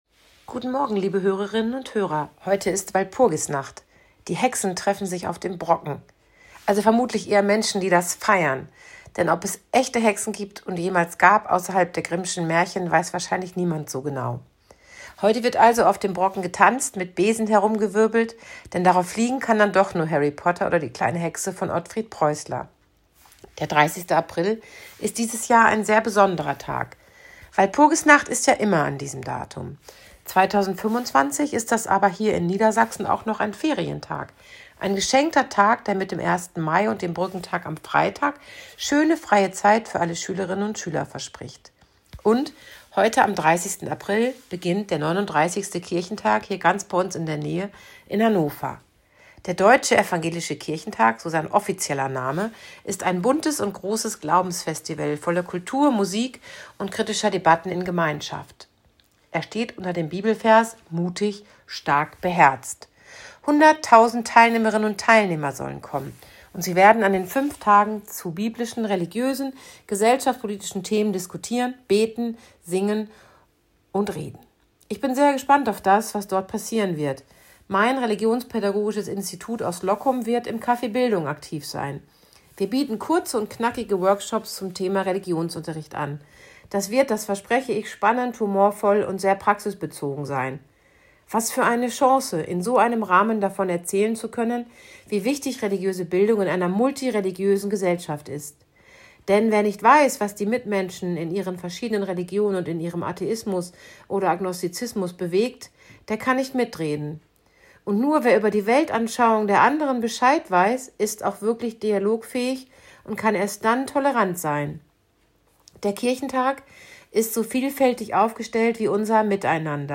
Radioandacht vom 30. April